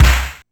Snare2.aif